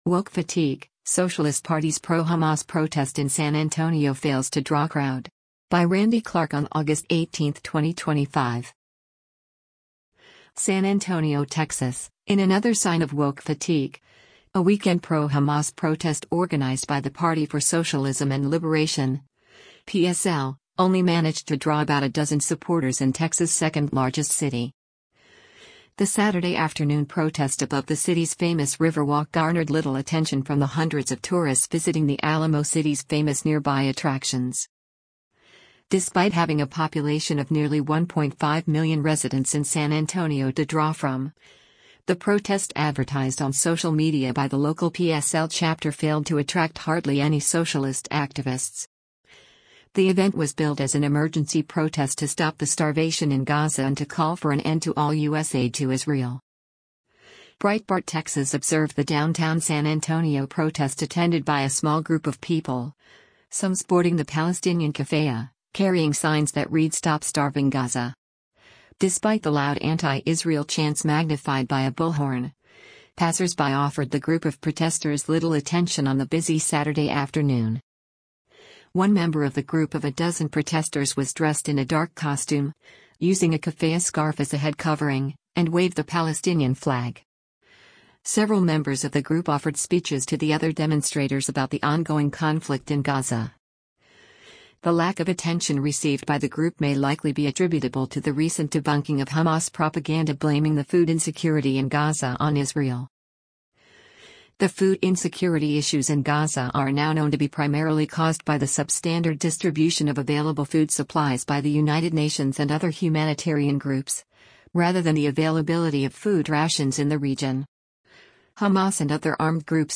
Despite the loud anti-Israel chants magnified by a bullhorn, passersby offered the group of protesters little attention on the busy Saturday afternoon.
Several members of the group offered speeches to the other demonstrators about the ongoing conflict in Gaza.